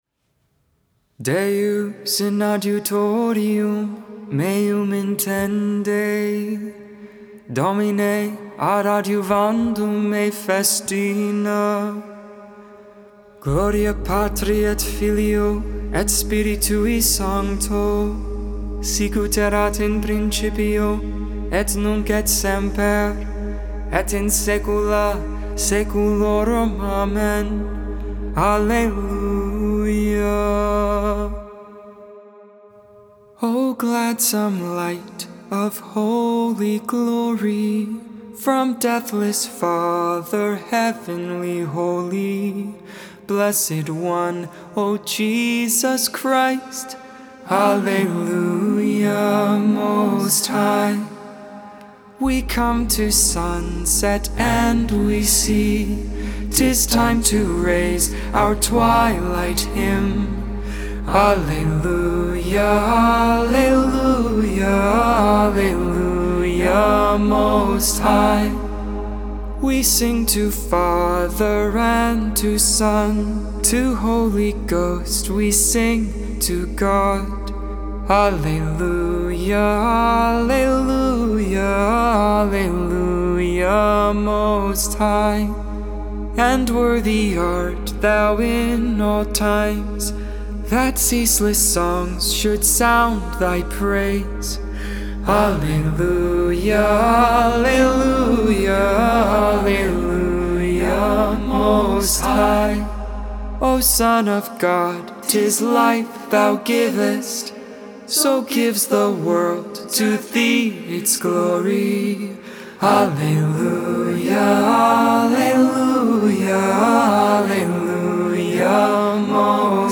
4.15.21 Vespers, Thursday Evening Prayer
Vespers, Evening Prayer for the 2nd Thursday of Eastertide.
Magnificat: Luke 1v46-55 (English, tone 8) Intercessions:King of glory, hear our prayer.